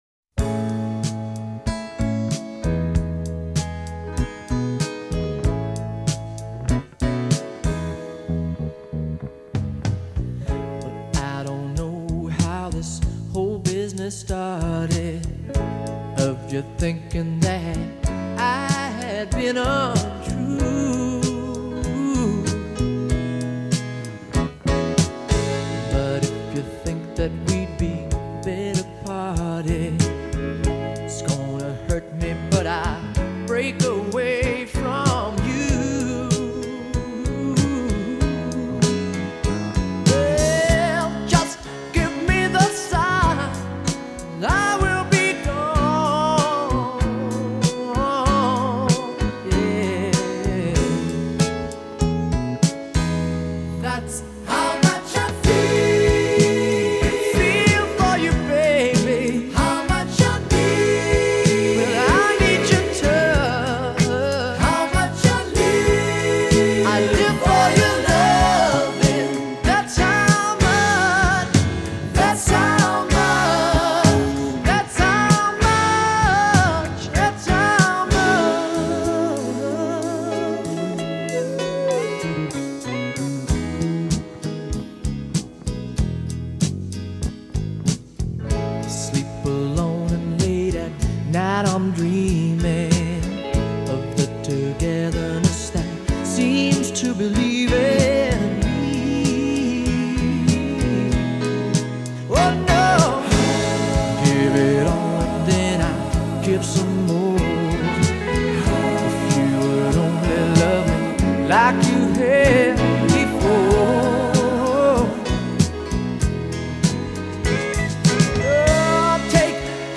banda de rock estadounidense